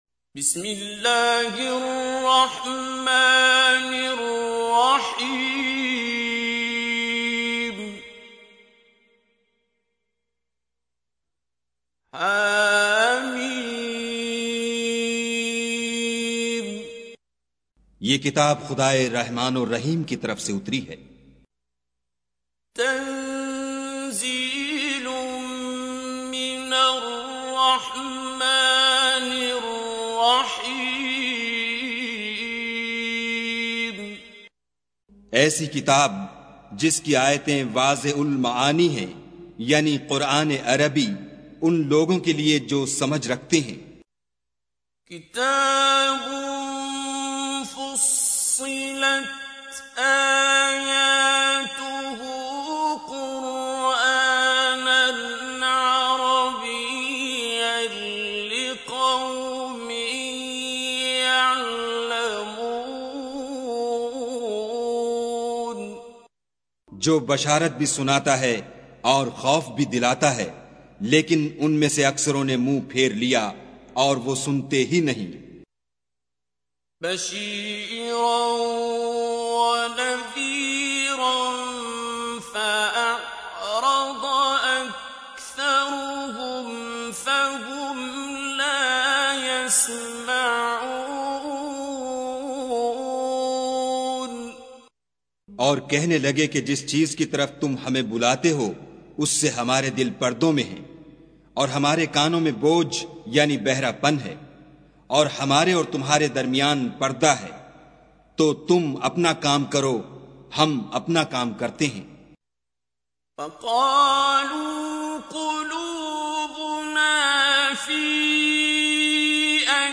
Surah Repeating تكرار السورة Download Surah حمّل السورة Reciting Mutarjamah Translation Audio for 41. Surah Fussilat سورة فصّلت N.B *Surah Includes Al-Basmalah Reciters Sequents تتابع التلاوات Reciters Repeats تكرار التلاوات